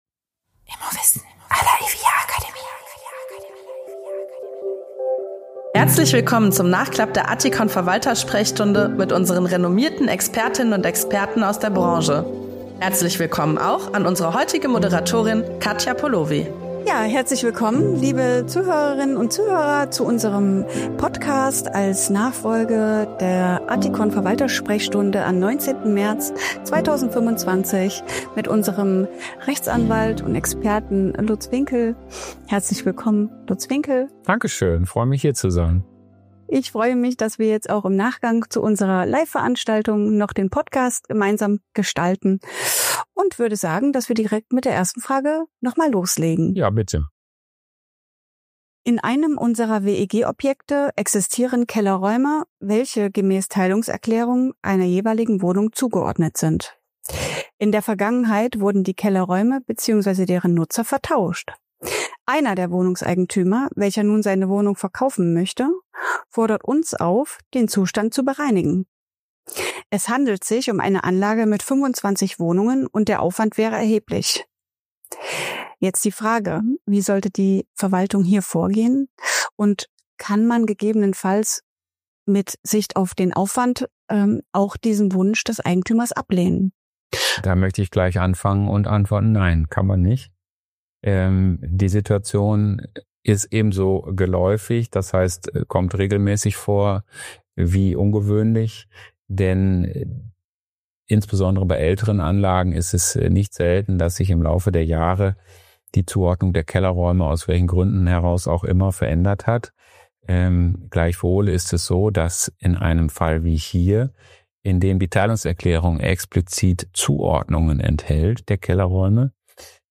90 Minuten lang beantworten unsere Expertinnen und Experten monatlich in der ATTIKON-Verwalter-Sprechstunde live Ihre Fragen.